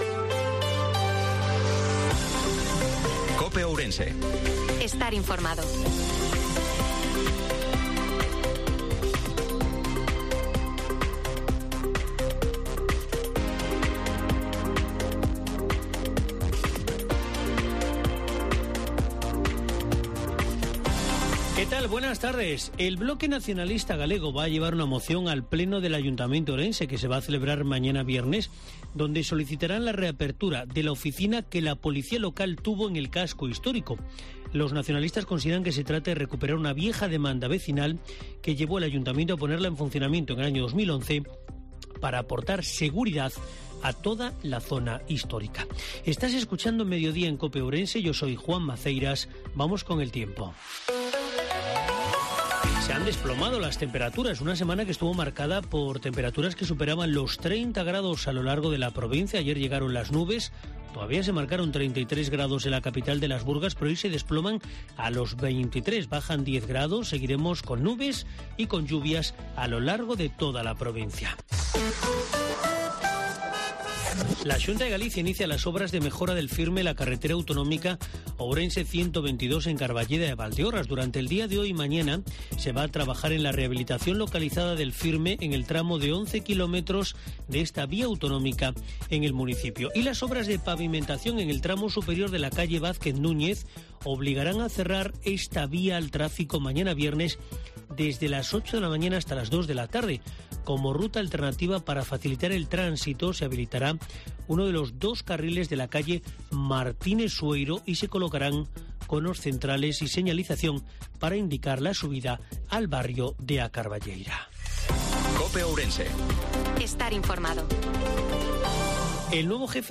INFORMATIVO MEDIODIA COPE OURENSE-04/05/2023